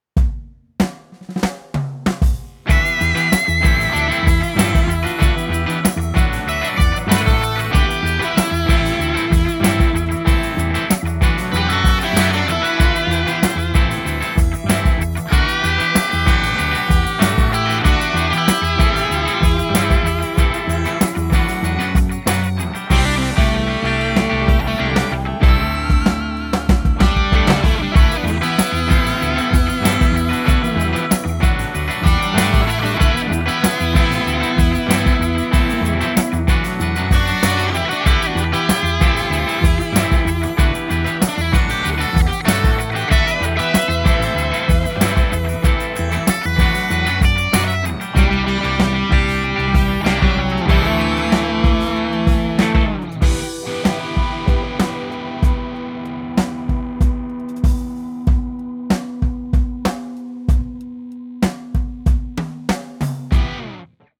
Just music for now.
Love me some harmonized guitars!